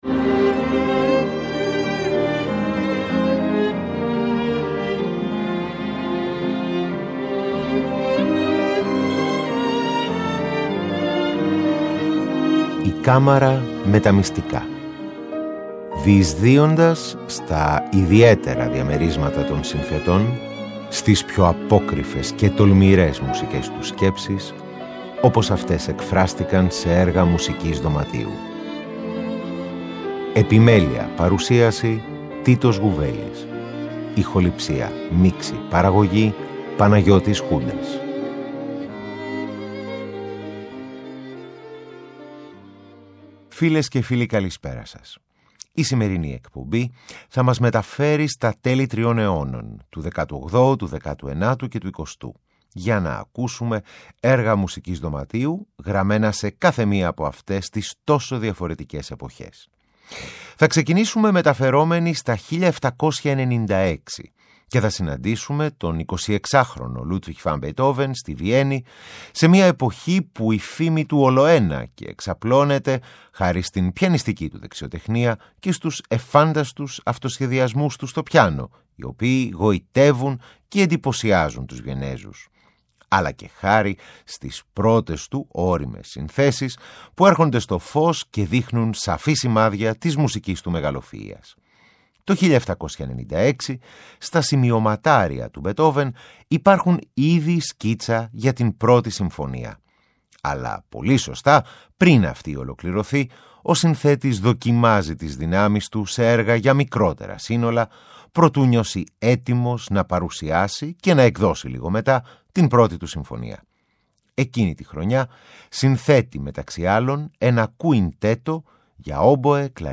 Ανάμεσά τους, ένα ανάλαφρο μουσικό διάλειμμα μας θυμίζει τη γοητεία του συνδυασμού φλάουτου και κιθάρας.